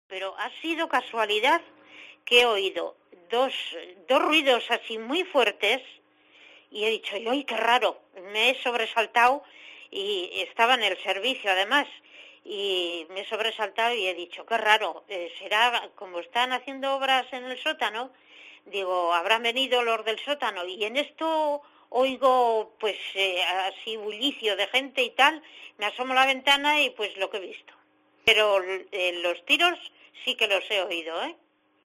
AUDIO: Una vecina de Mondragón relata cómo escuchó los disparos